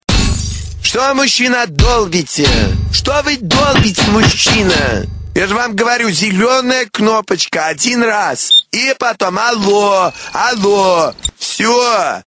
Главная » Рингтоны » Рингтоны приколы